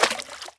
etfx_explosion_liquid3.wav